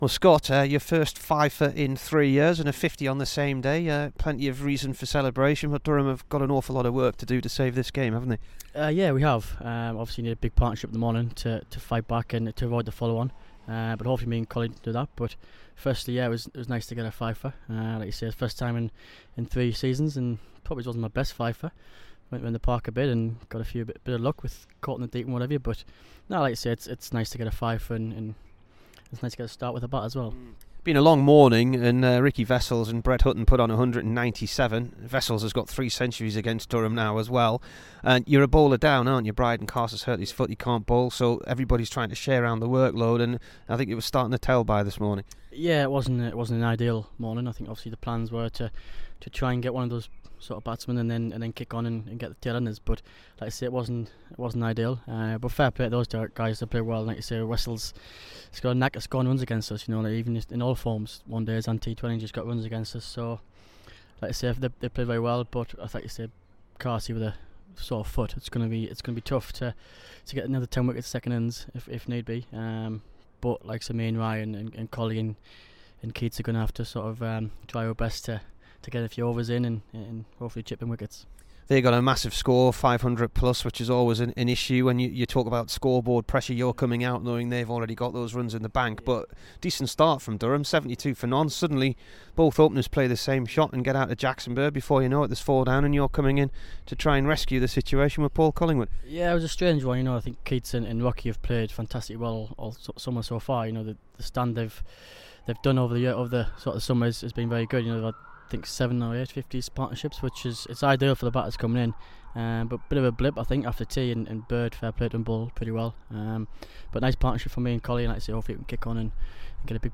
Here is the Durham player after taking 5-79 on day two at Notts and scoring 59 not out.